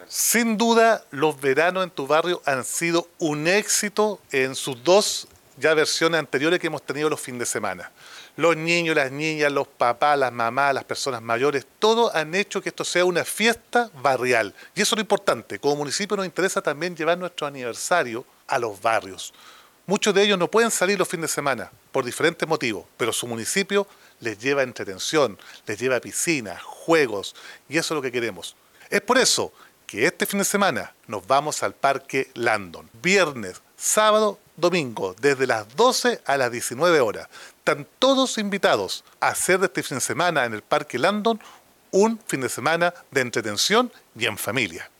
Roberto-Neira-alcalde-de-temuco-verano-en-tu-barrio.mp3